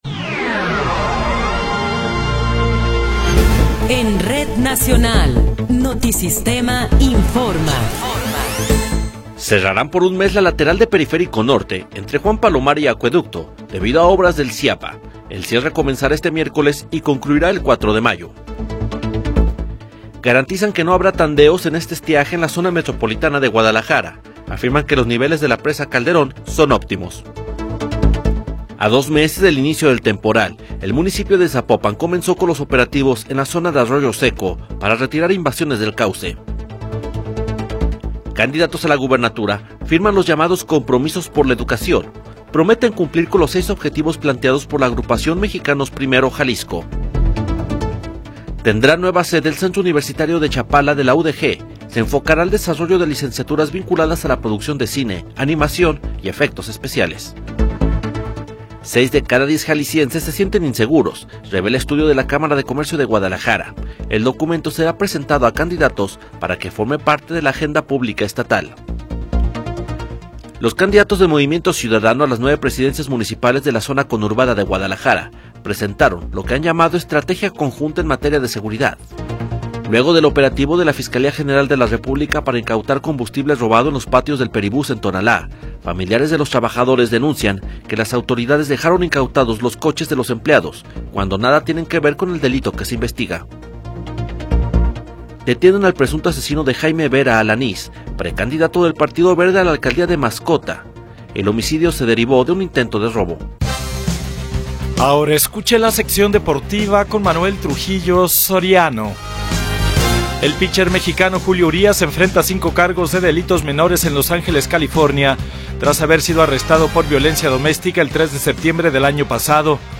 Noticiero 21 hrs. – 9 de Abril de 2024